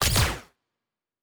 pgs/Assets/Audio/Sci-Fi Sounds/Weapons/Weapon 07 Shoot 1.wav at 7452e70b8c5ad2f7daae623e1a952eb18c9caab4
Weapon 07 Shoot 1.wav